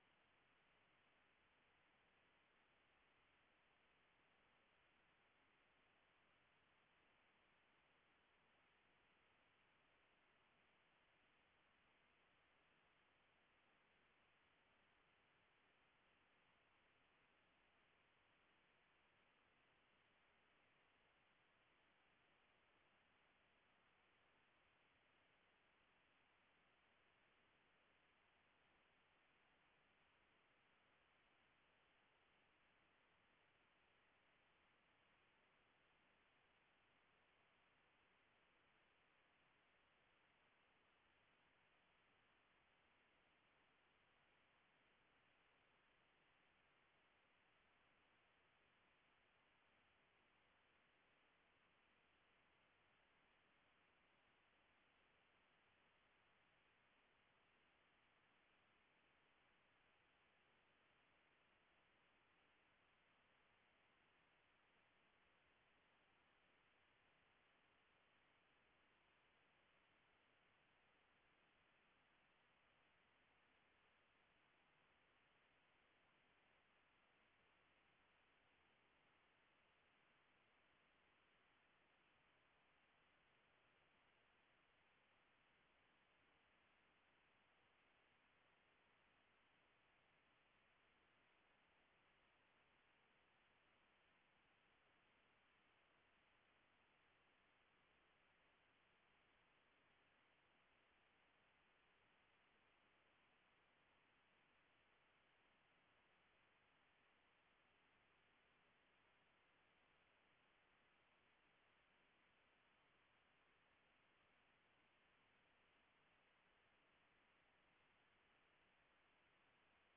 The Voyager 1 spacecraft was copied at the Bochum 20m dish at a distance of 102 AU =9,481,500,540 miles using an SDR-IQ receiver.
Save this file to disk and open with SpectraVue as an I/Q WAV file: voyager1a.wav